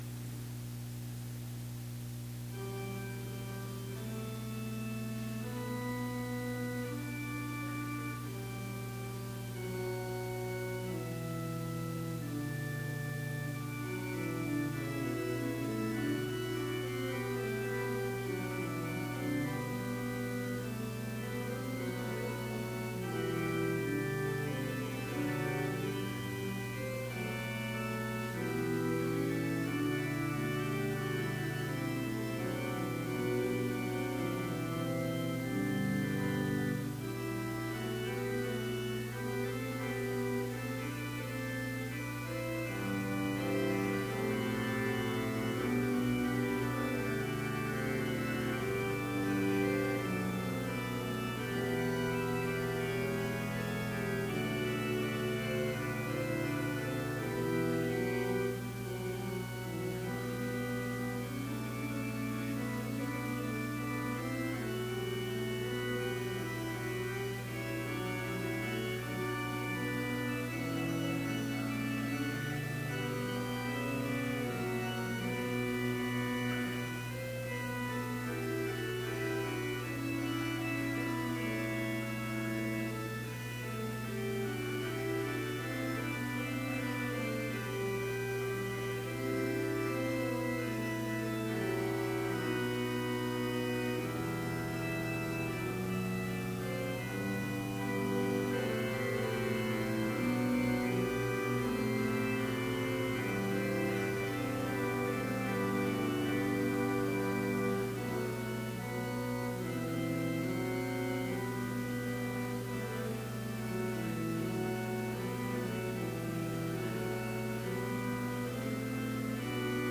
Complete service audio for Chapel - February 27, 2018